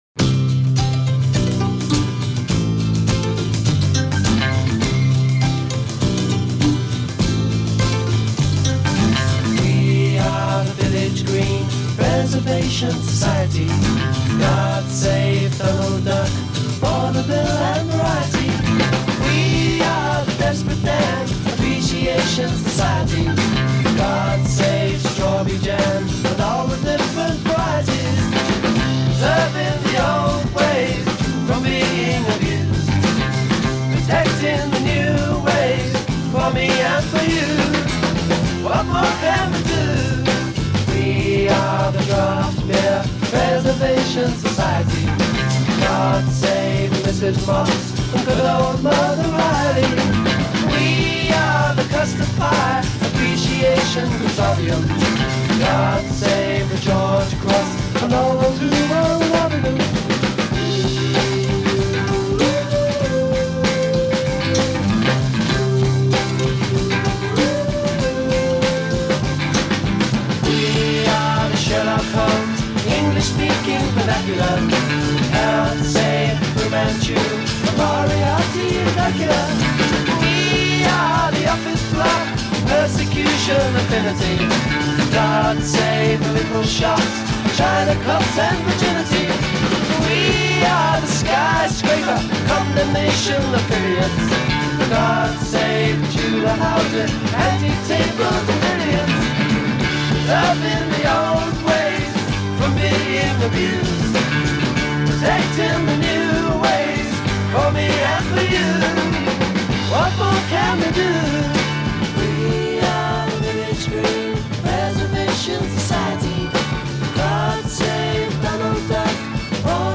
A fantastic 1973 live version